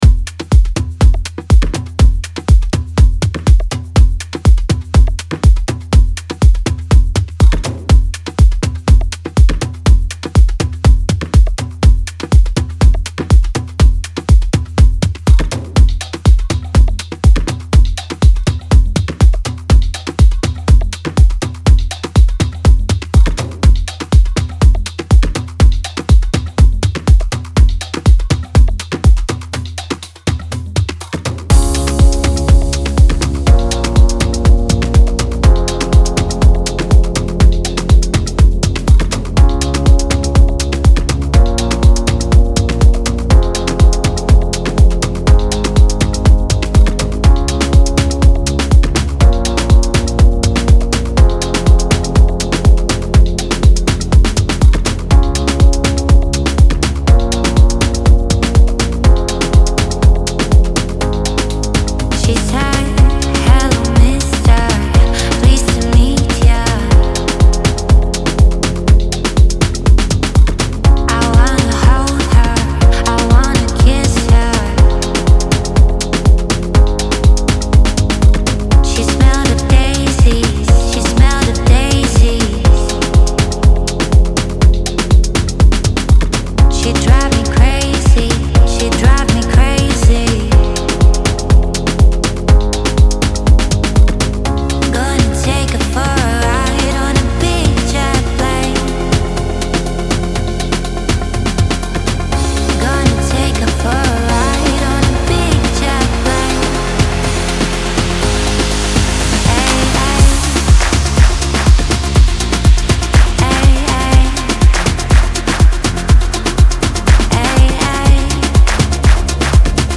• Жанр: House